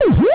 gs_eatfruit.au